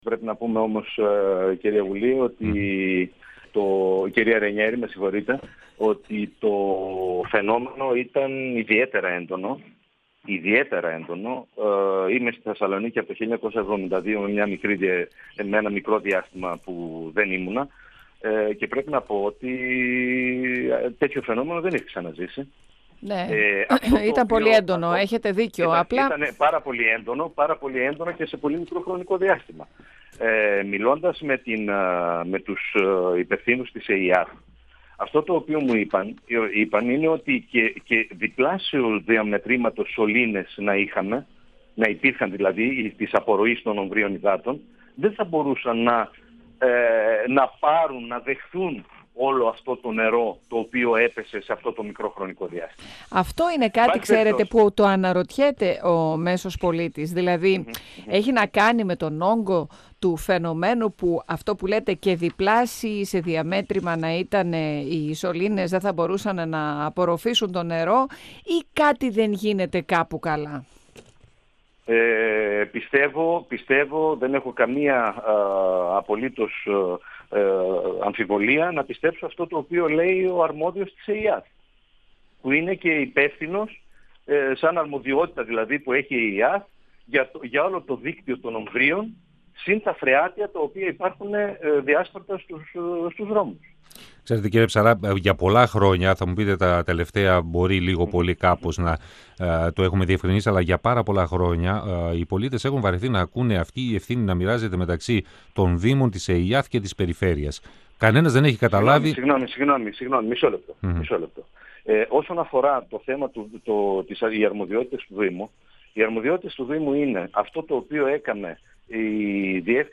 Ο γενικός γραμματέας του δήμου, Θωμάς Ψαρράς, στον 102FM του Ρ.Σ.Μ. της ΕΡΤ3
Συνέντευξη